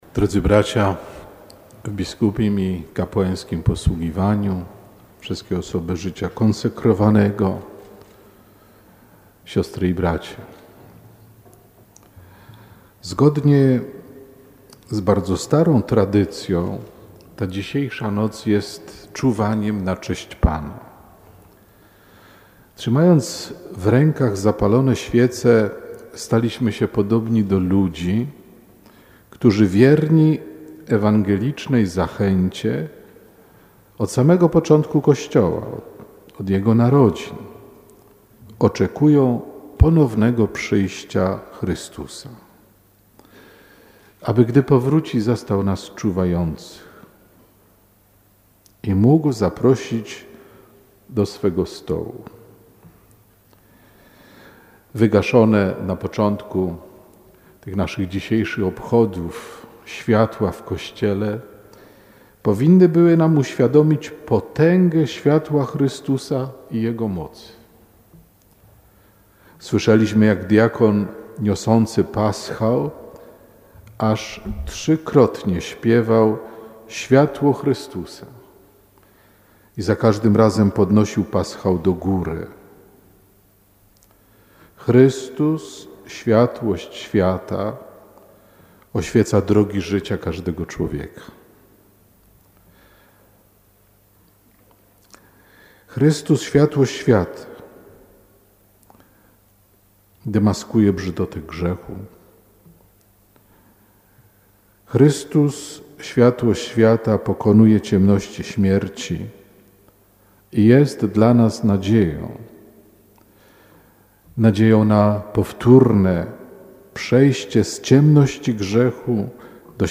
abp Kupny: święty - wcale nie znaczy bezgrzeszny [HOMILIA] - Radio Rodzina
Metropolita Wrocławski przewodniczył transmitowanej przez Radio Rodzina w wielkosobotni wieczór liturgii.